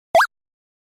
Free UI/UX sound effect: Achievement Unlock.
Achievement Unlock
373_achievement_unlock.mp3